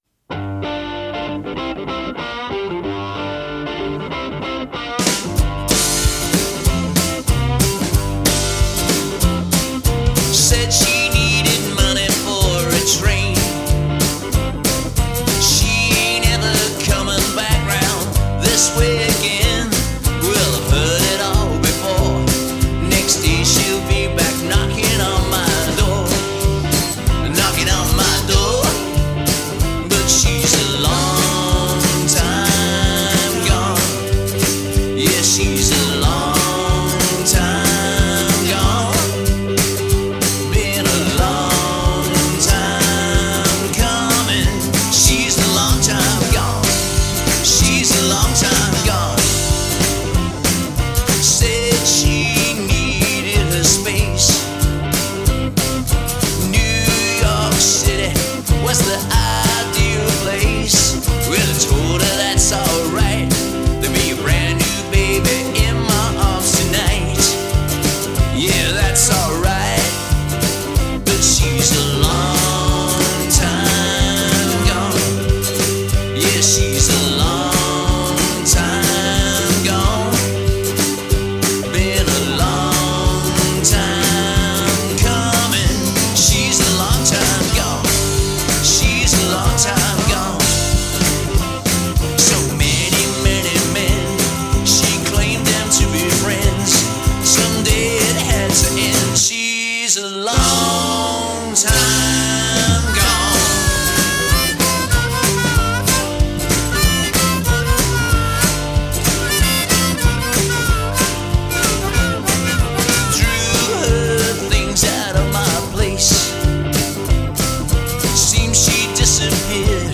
Слегка грязноватый звук